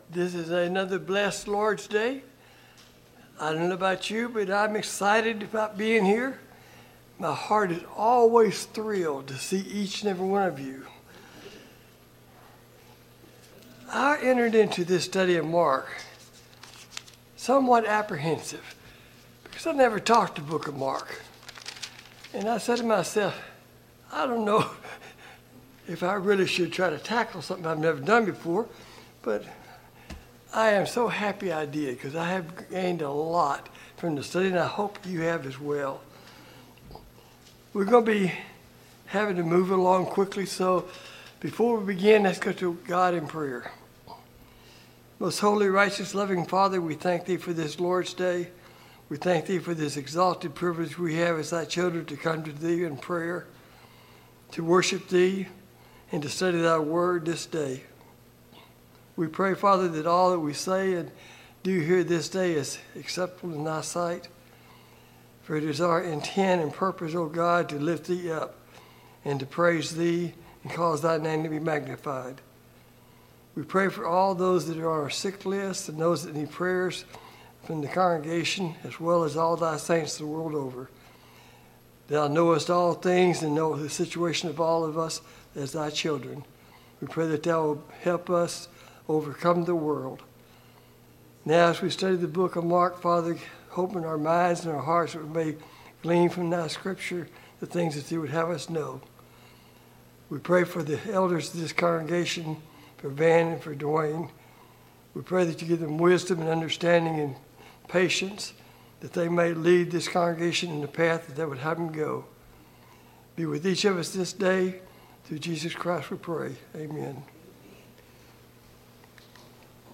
Passage: Mark 6 Service Type: Sunday Morning Bible Class